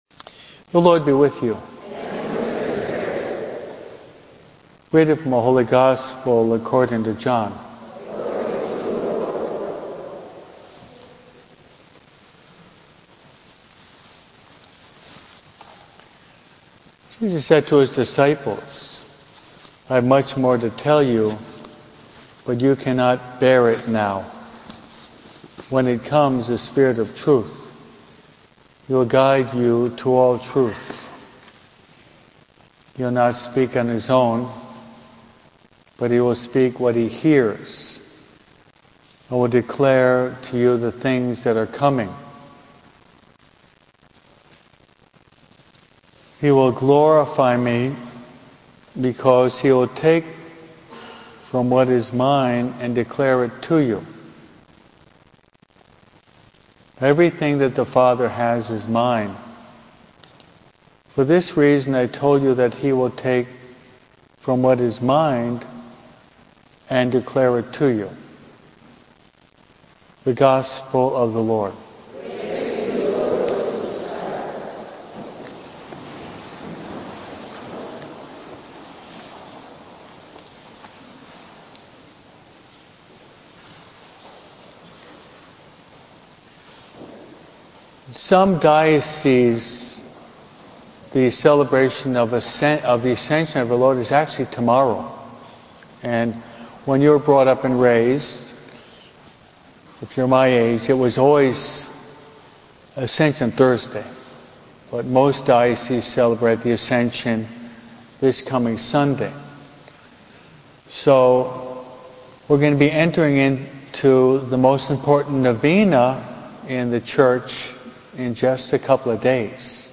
51105E-MASS-GIFTS-OF-THE-HOLY-SPIRIT.mp3